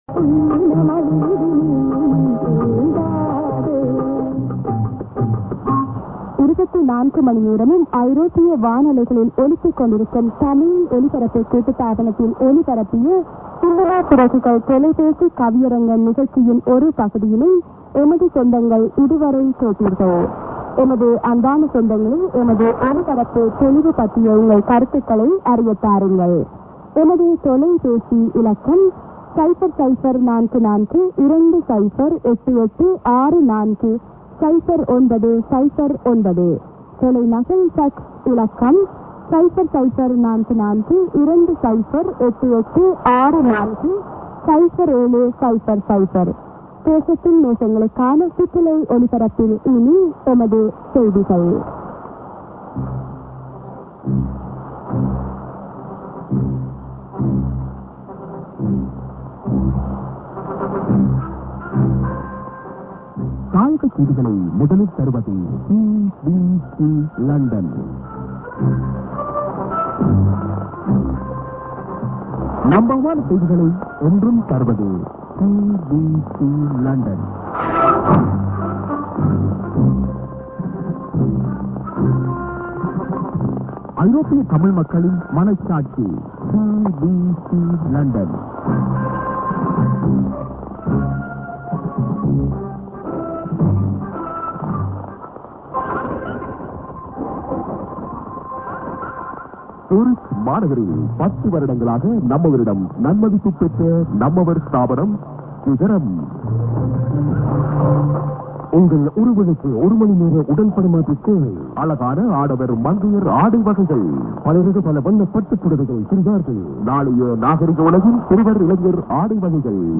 via DTK Julich Mid. music->ANN(women)->music->01'05":ID+ADDR(man)->music->03'10:ID(women)